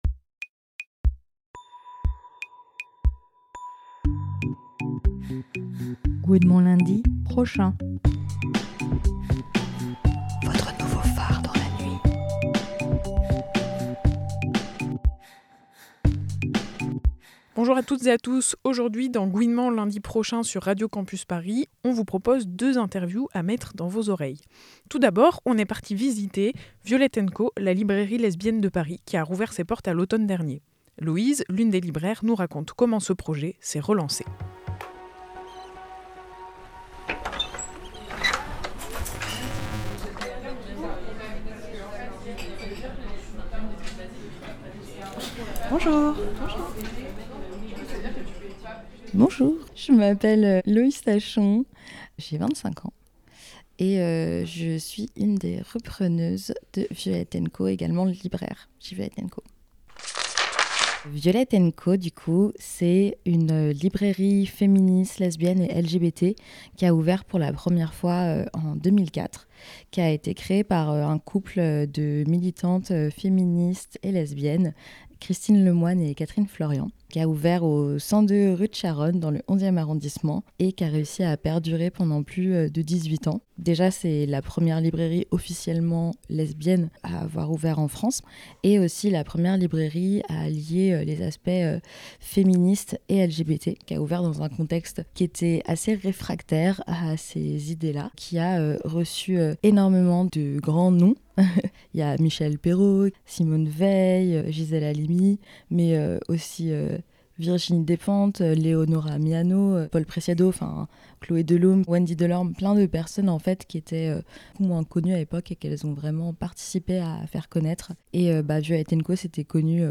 Dans ce nouvel épisode de Gouinement Lundi Prochain, on vous propose deux interviews à glisser dans vos oreilles.